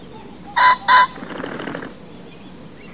Fagiano comune – Fagiàn
Phasianus colchicus
Il maschio produce un sonoro e stridulo ‘korrk-korrk’ bisillabico.
Fagiano_Comune_Phasianus_colchicus.mp3